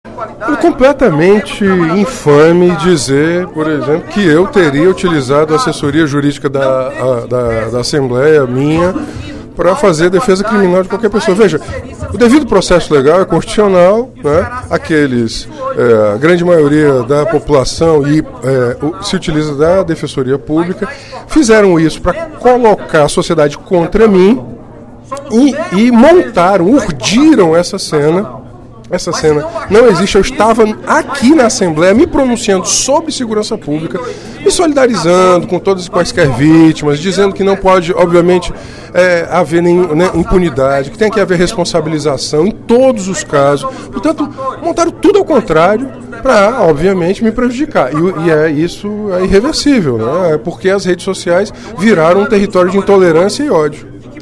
O deputado Renato Roseno (Psol) explicou, em pronunciamento no primeiro expediente da sessão plenária desta terça-feira (23/02), os boatos que surgiram em redes sociais.